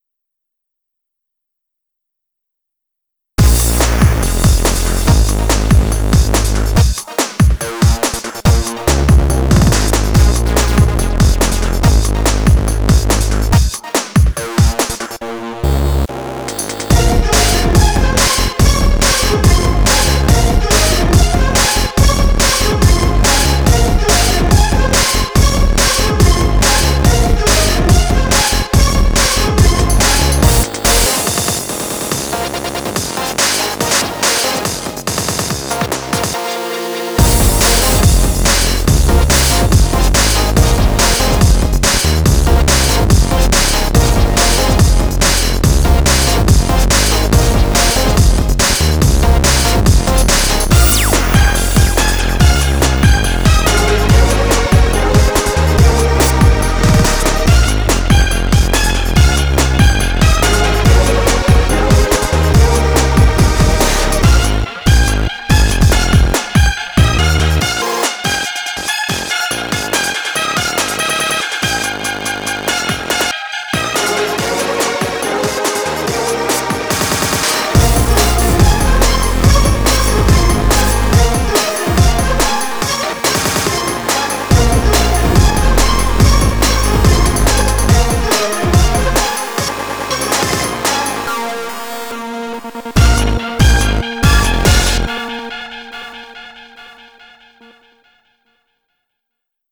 BPM142
Audio QualityMusic Cut